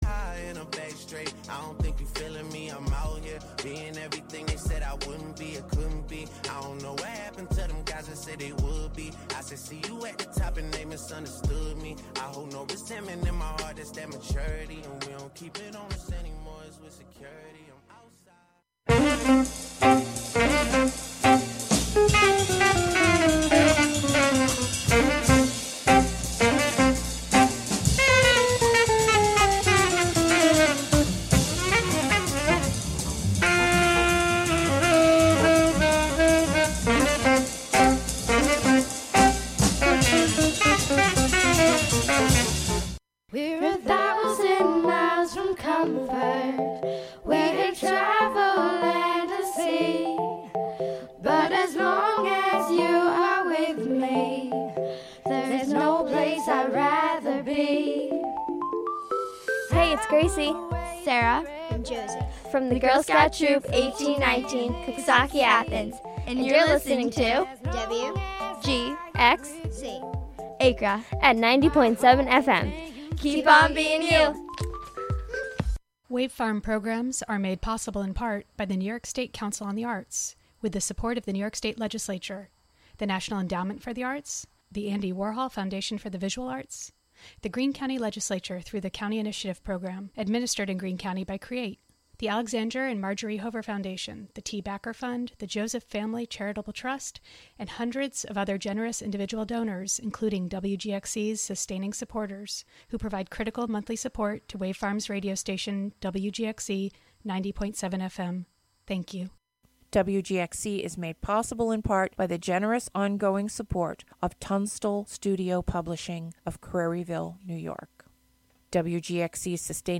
This music mix show
Featuring gospel, inspirational, soul, R&B, country, christian jazz, hip hop, rap, and praise and worship music of our time and yesteryear; interwoven with talk, interviews and spiritual social commentary